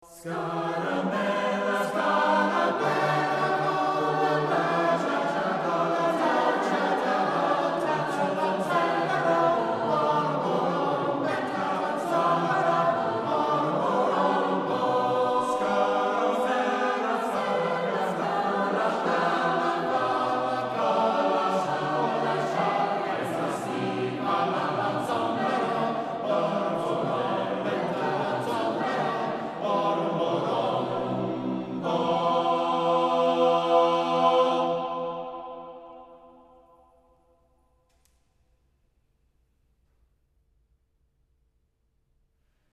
Choir Performances
Spring 2003 Josquin Concert
Recorded at Mont LaSalle Christian Brothers in Napa, Califonia